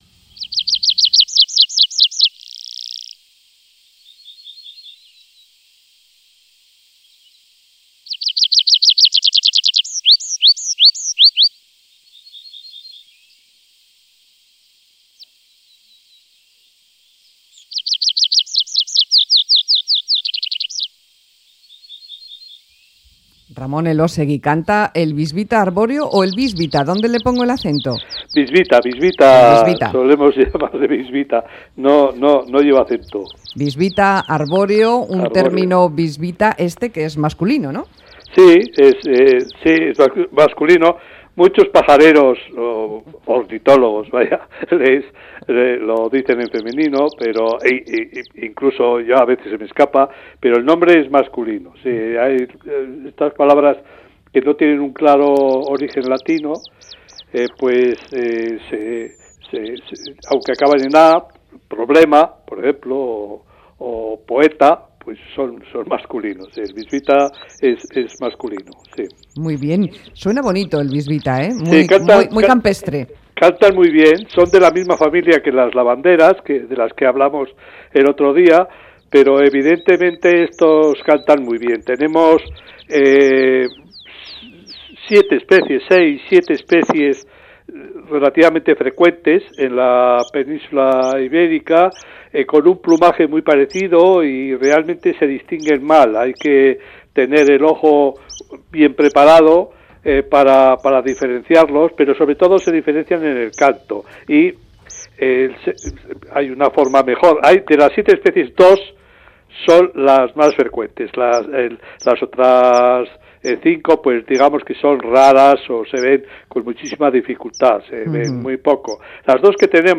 un pájaro típico de campiña abierta. Más Que Palabras, Radio Euskadi.